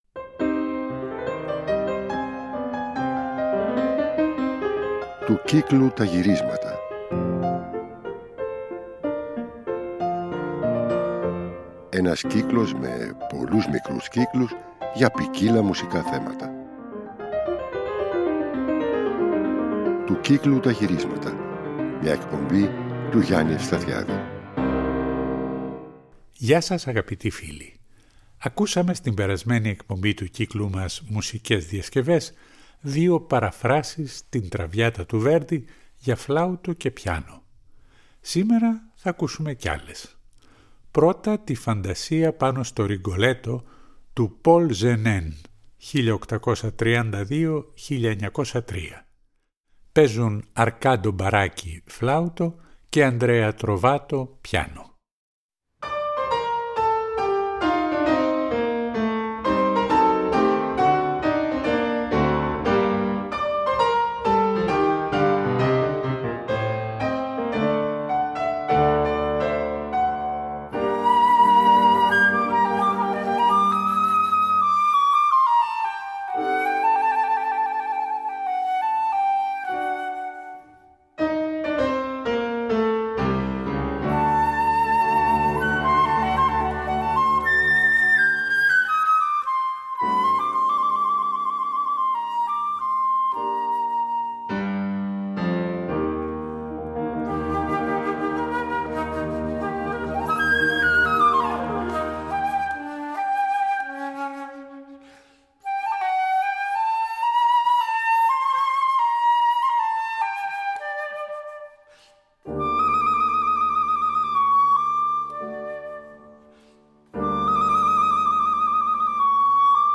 ορχηστρικές διασκευές